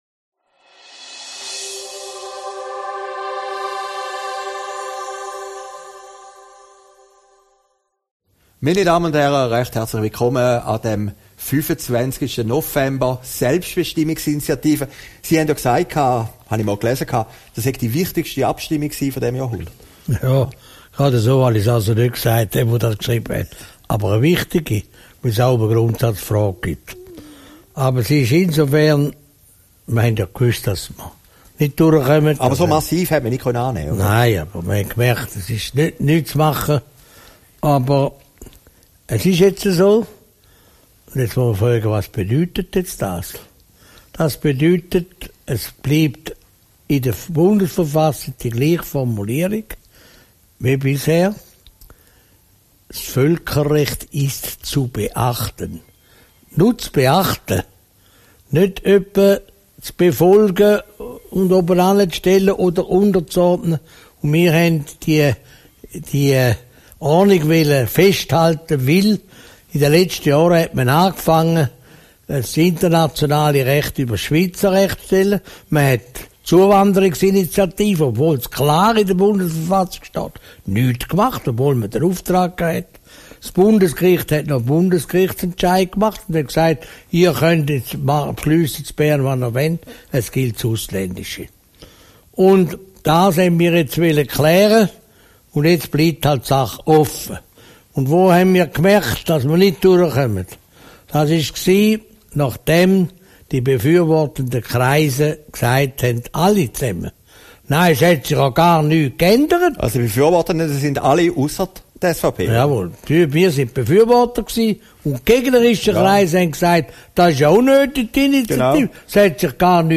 Aufgezeichnet in Herrliberg, 25. November 2018